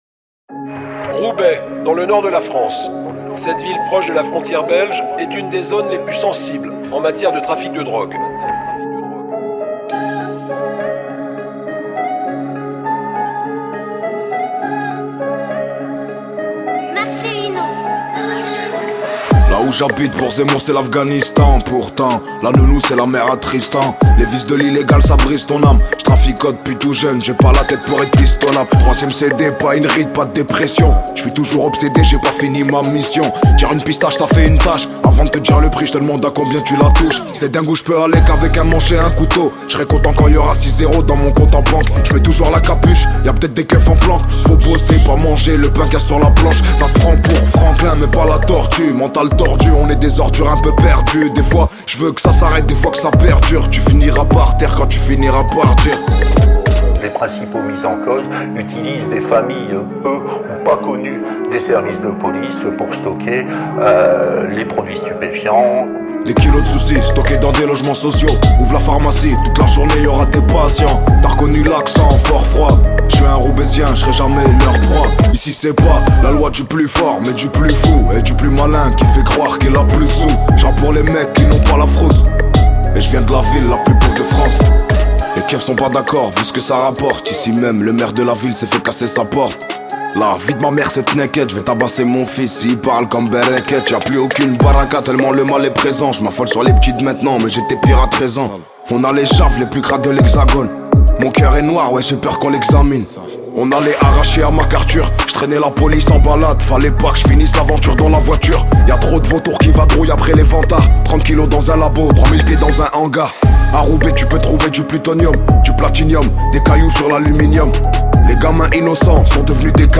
french rap Télécharger